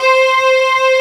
14 STRG C4-L.wav